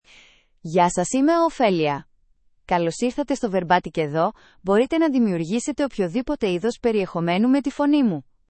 FemaleGreek (Greece)
OpheliaFemale Greek AI voice
Voice sample
Listen to Ophelia's female Greek voice.
Ophelia delivers clear pronunciation with authentic Greece Greek intonation, making your content sound professionally produced.